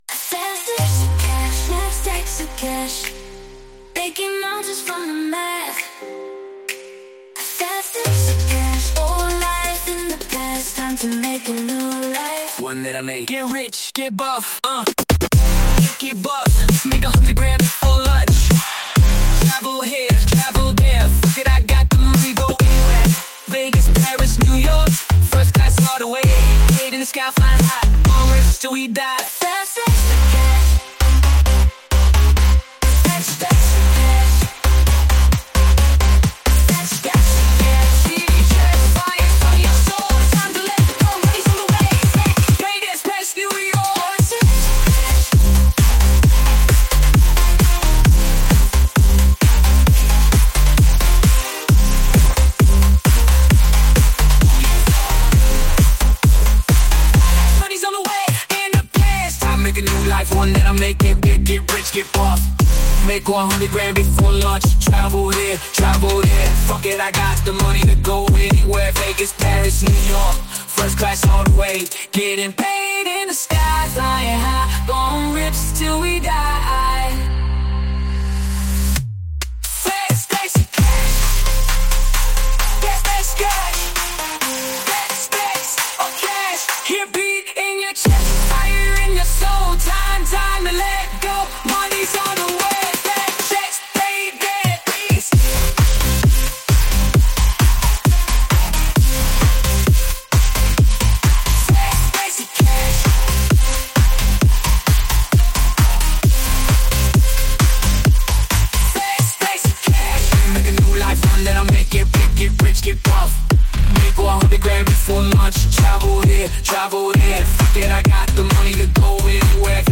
Apply fade out effect to the last 2.5 seconds